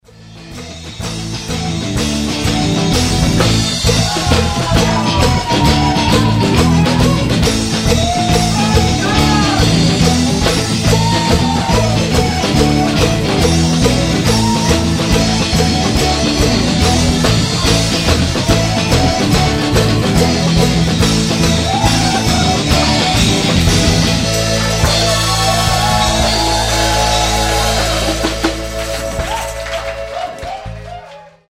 These sound clips (below) were recorded at a live Scottish Ceilidh themed event:
Please note: the band's instrumentation for the ceilidh material is: Drums, bass, guitar, keys/piano and the maximum duration of the band's ceilidh set is 45 mins.
CeilidhStripTheWillow.mp3